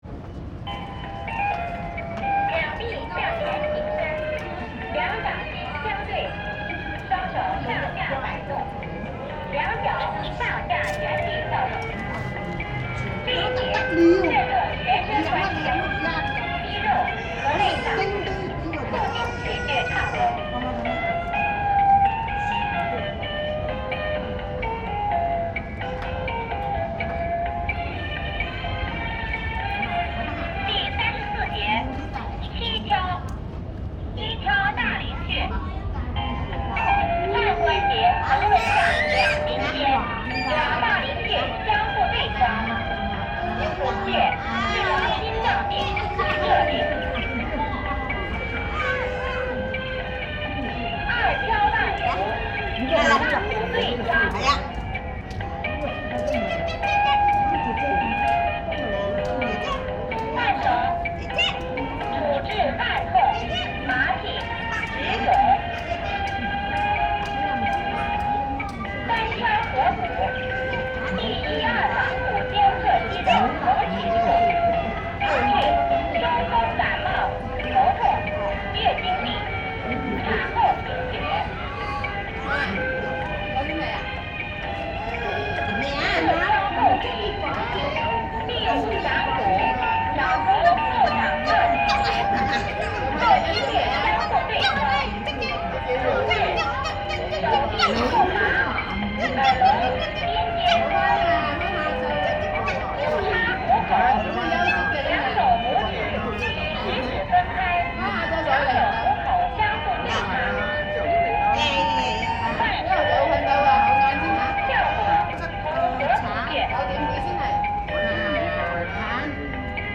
Sound recorded in Beijing, Shanghai, Hong Kong and Macao.
- Workout music accompanying workout, Macao (1) (4:06)
workout_music_macao_1.mp3